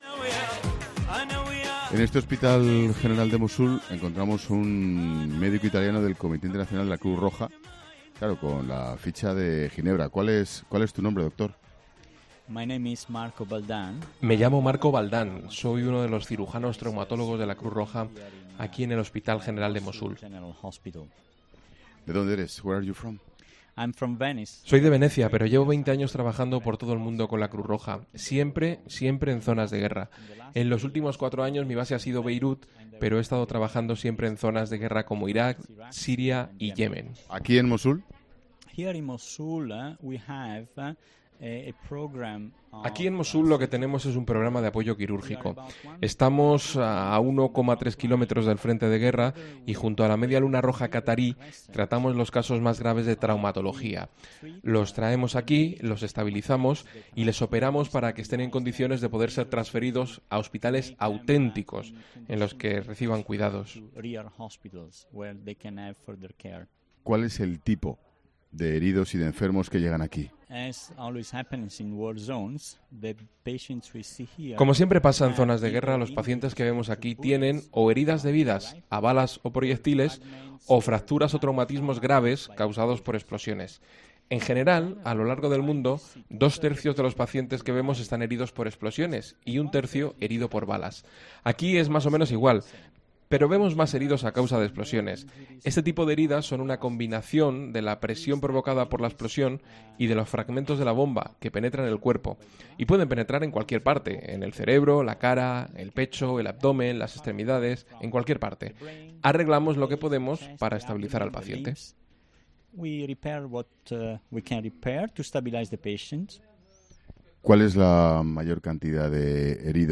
Este lunes, 'La Tarde' de Ángel Expósito se emite desde Mosul, ciudad iraquí que sufre la difícil situación de los refugiados que huyen del Daesh.